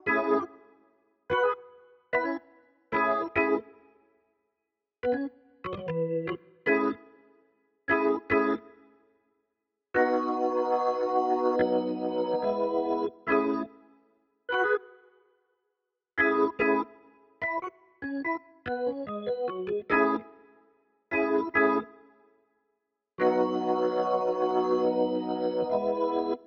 02 organ B.wav